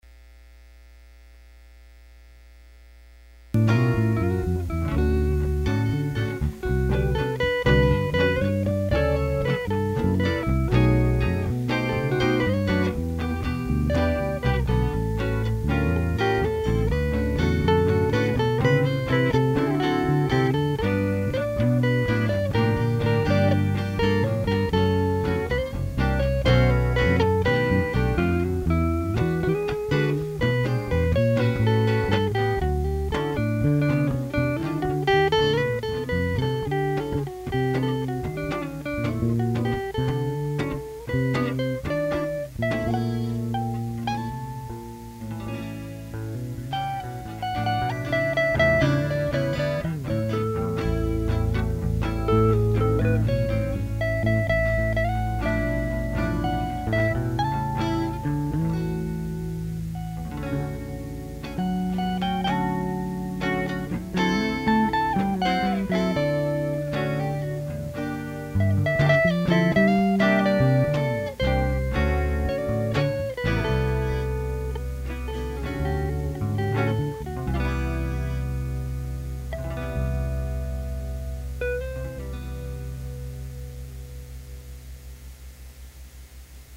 Opera Improvisation